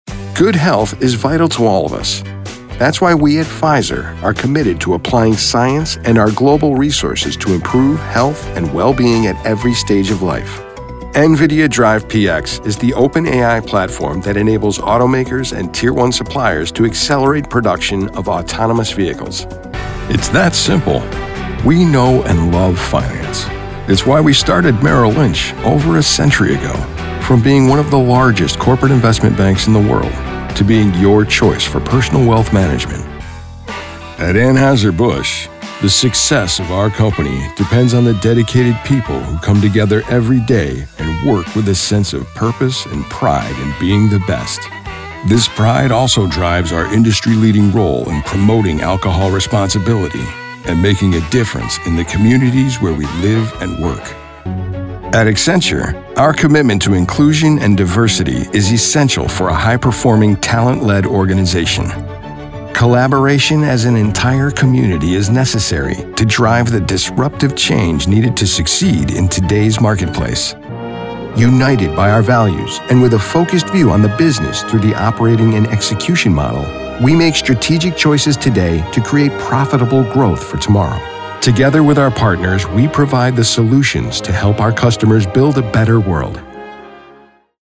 SOURCE-CONNECT Certified US MALE VOICOVER with HOME STUDIO
• BOOTH: Whisper Room, acoustically-treated
straight
MIX13-straight.mp3